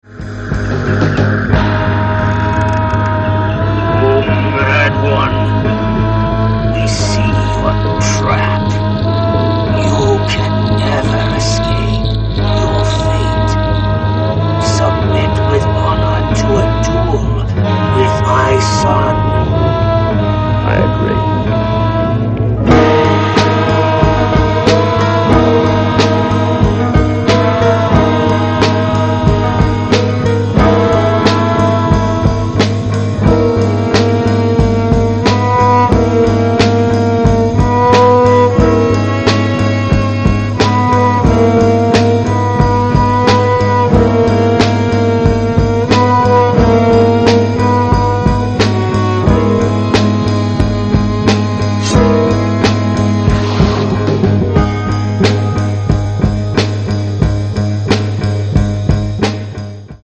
instrumental souljazz style.